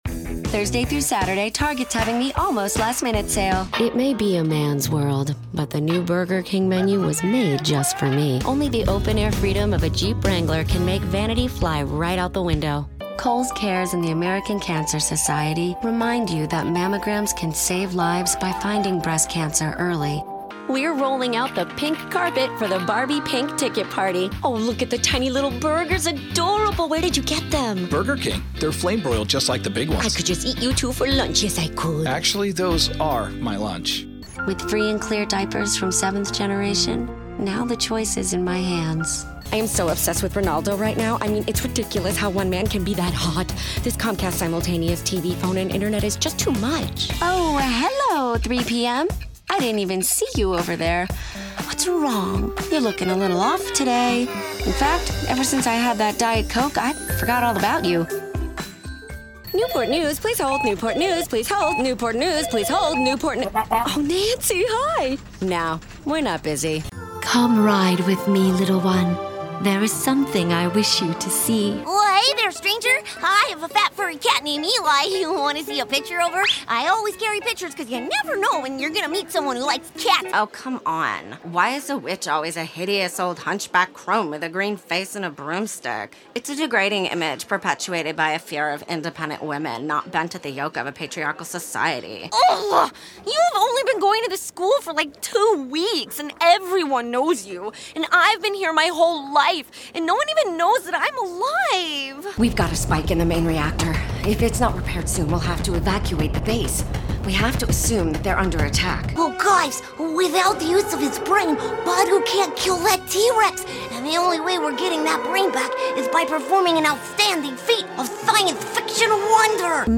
animation 🎬